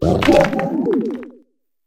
houndstone_ambient.ogg